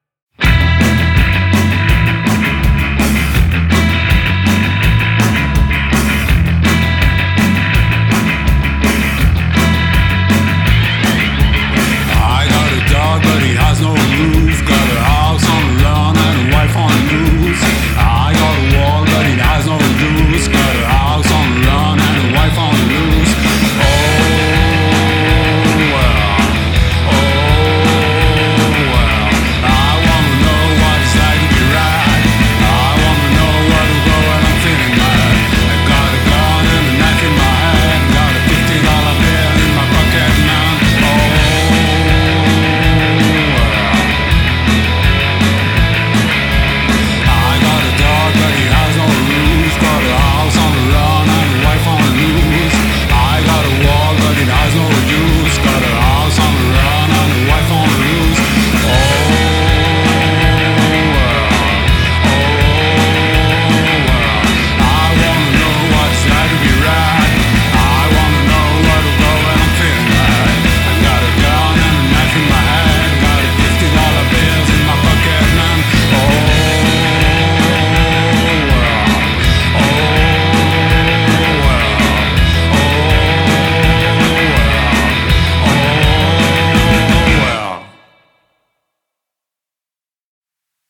power/indiepop musclée et concise